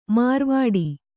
♪ mārvāḍi